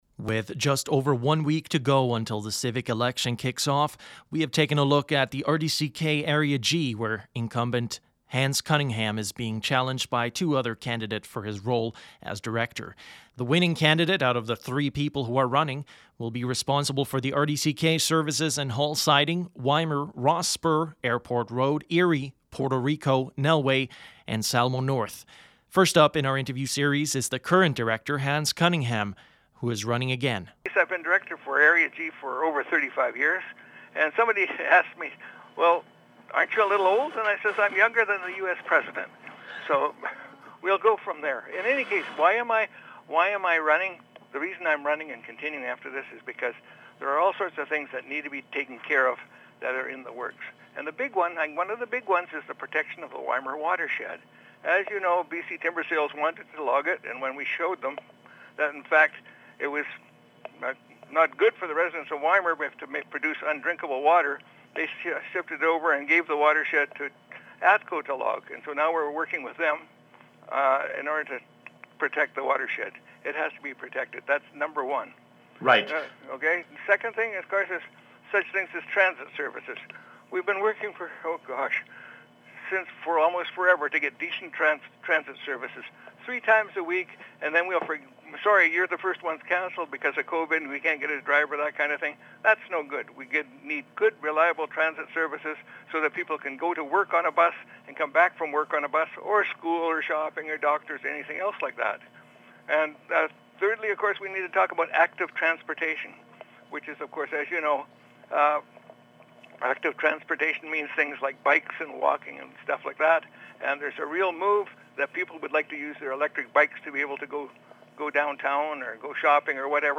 With the civic election approaching, Kootenay Co-Op Radio has spoken to the three candidates vying for power as director for Area G in the Regional District of Central Kootenay.